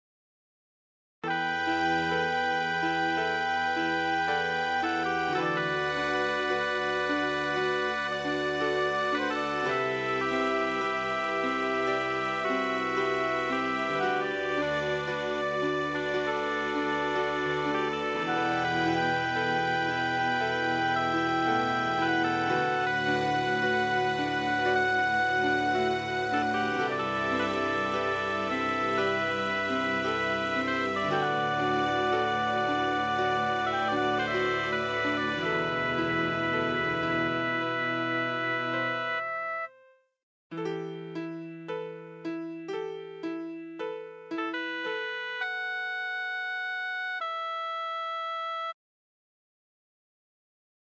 Short Emotional style piece with flute, Oboe and Strings